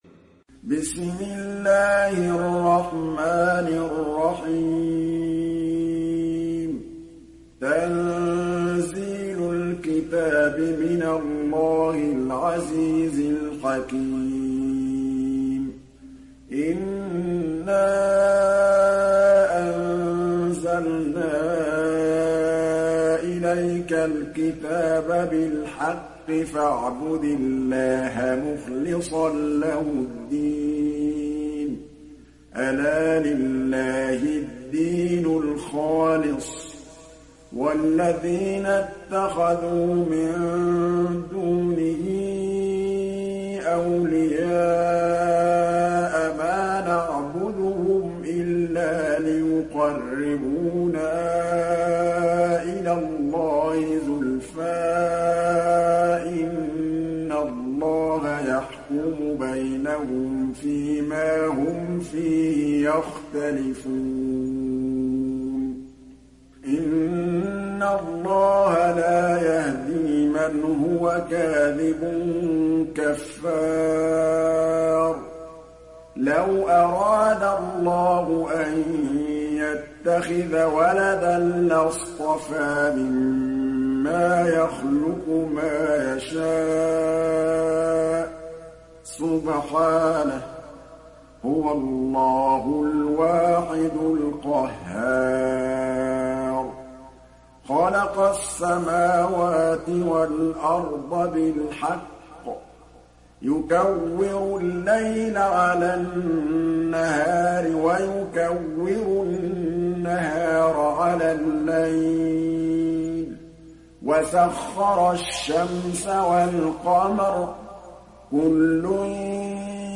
সূরা আয-যুমার mp3 ডাউনলোড Muhammad Mahmood Al Tablawi (উপন্যাস Hafs)